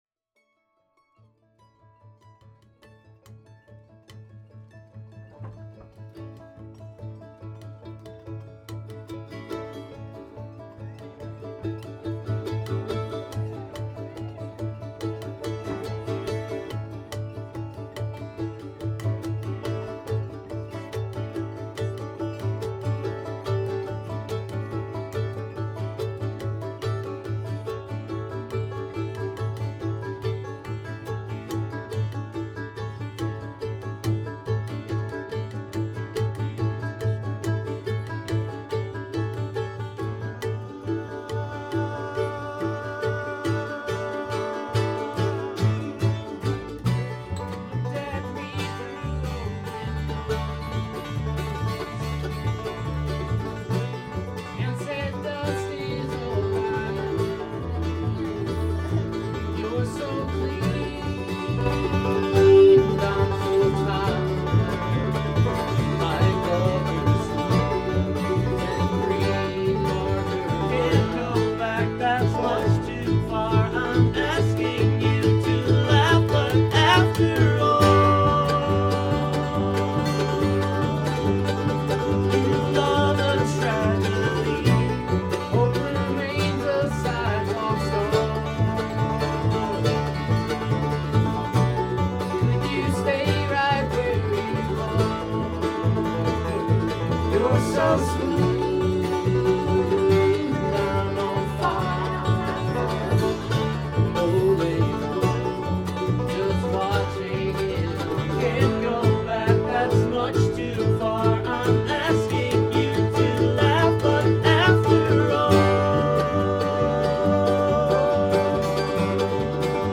Here’s a short set from one of my favorite Colorado Bands.